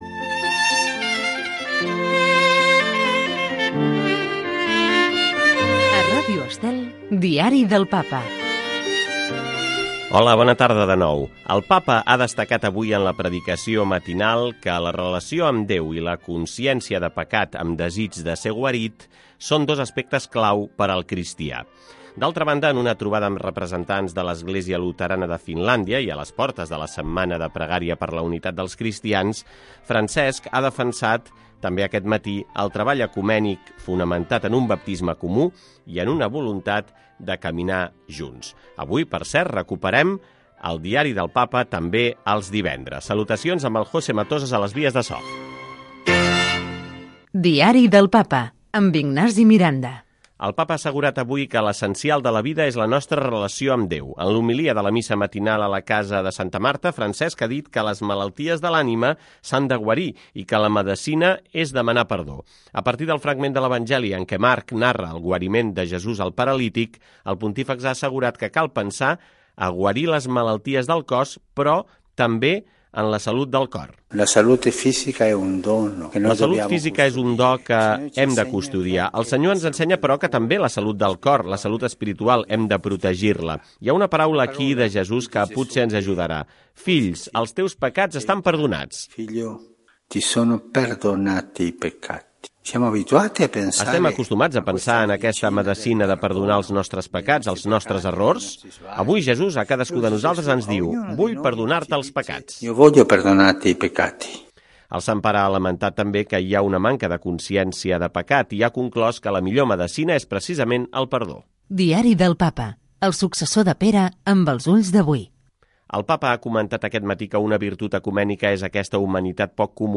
Cada dia, Ràdio Estel informa sobre l'actualitat del Papa Francesc i de la Santa Seu amb un espai informatiu en què es recullen les principals notícies del dia.